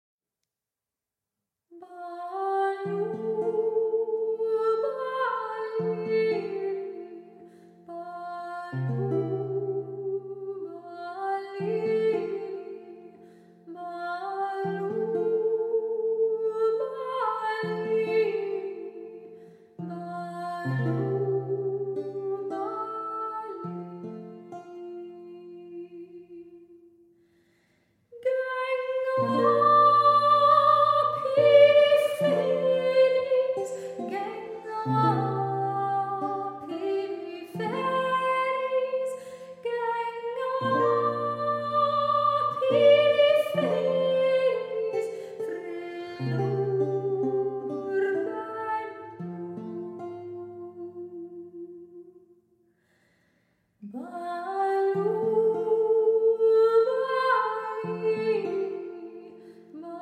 English, Scottish, and Irish lute songs